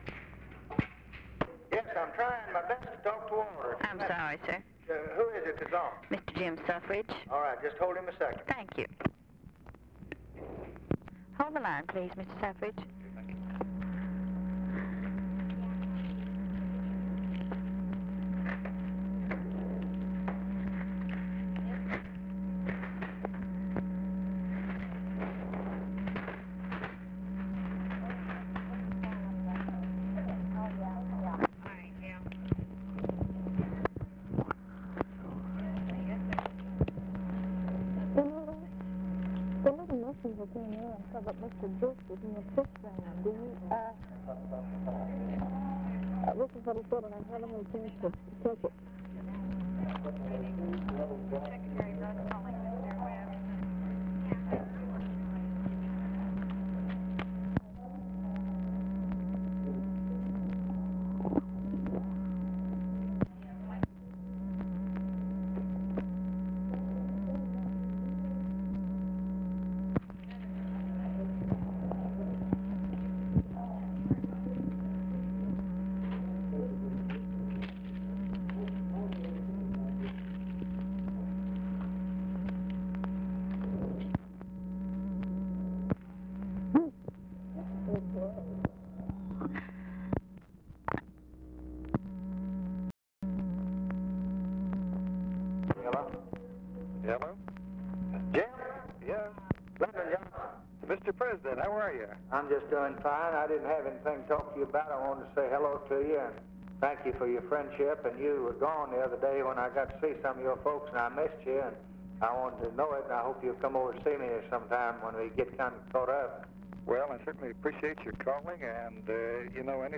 OFFICE CONVERSATION, December 09, 1963
Secret White House Tapes | Lyndon B. Johnson Presidency